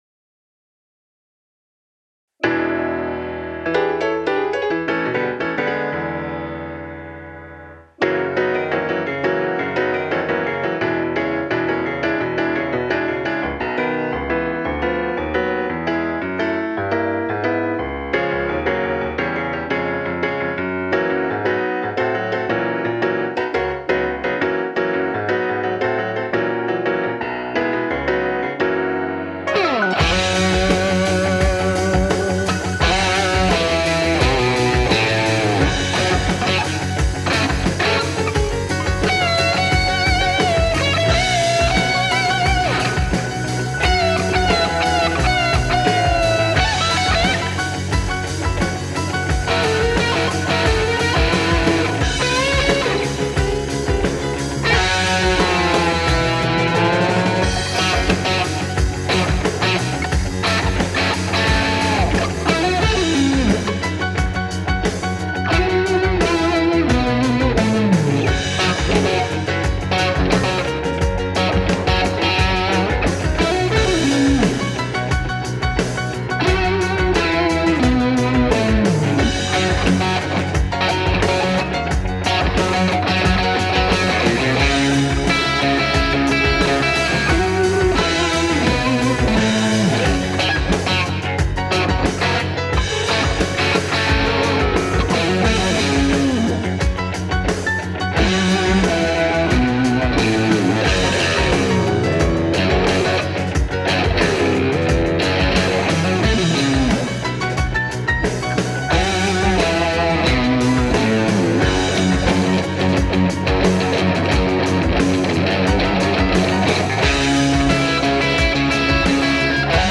BLUES/BLUESROCK/CLASSIC ROCK - COVERS FOR VOCALS
MY VERSION My version without singing